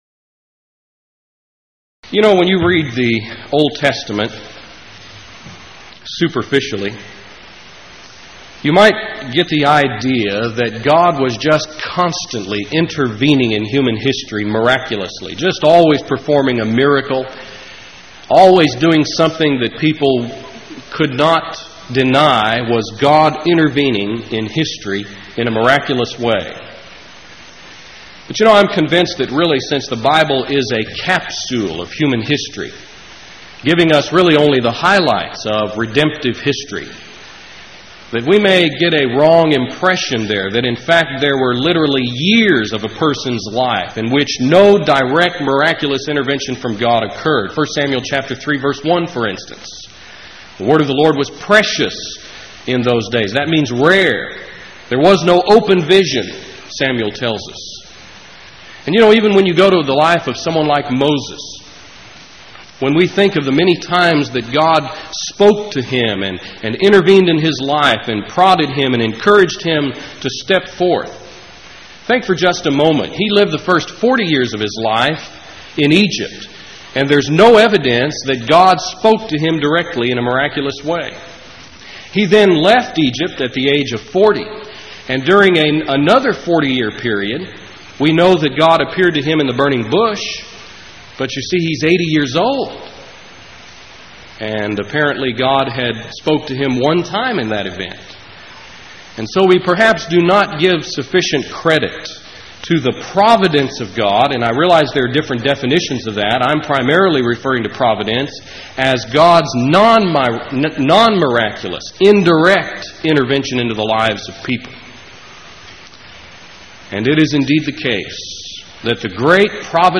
Series: Power Lectures